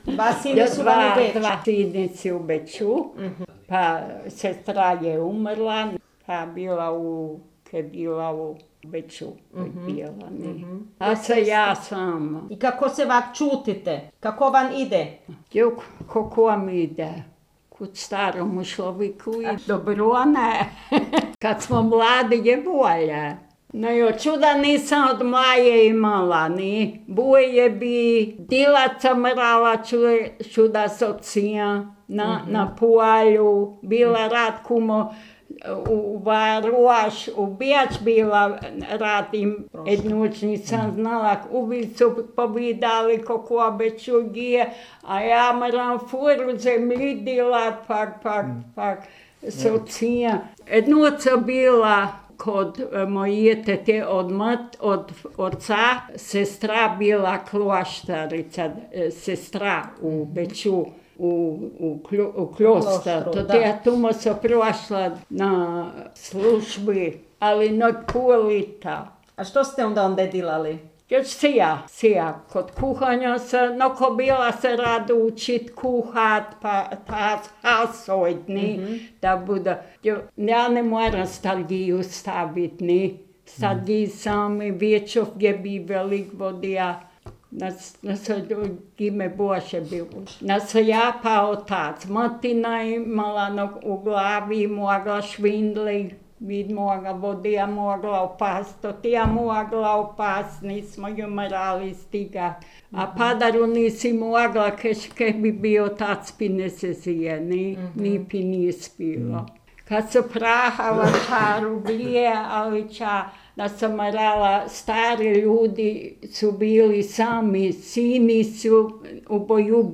Jezerjani – Govor
21_Jezerjani_govor.mp3